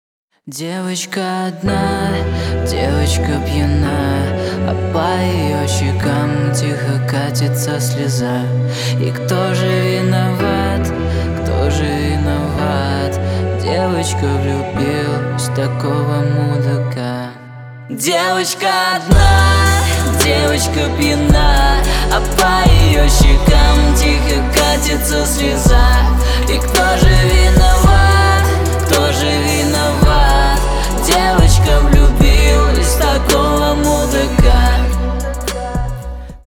мужской вокал
лирика
грустные
клавишные
медленные